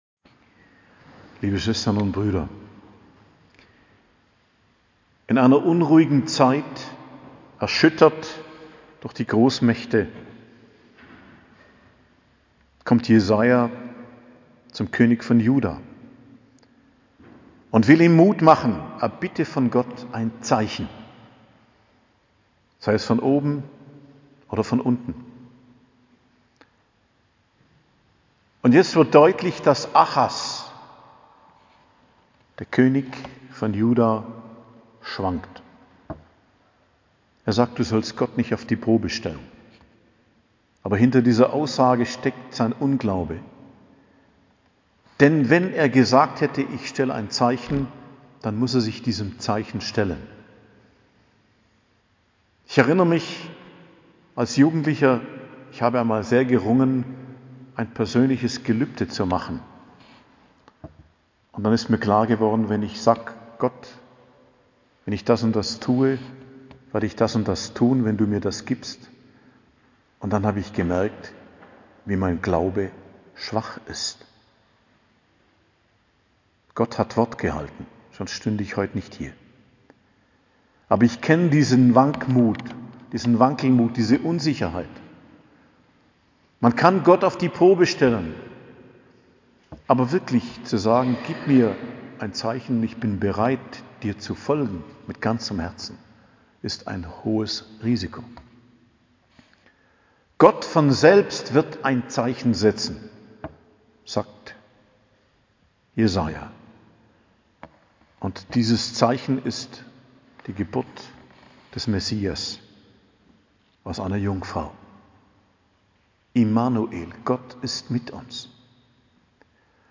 Predigt am Hochfest Verkündigung des Herrn, 25.03.2022 ~ Geistliches Zentrum Kloster Heiligkreuztal Podcast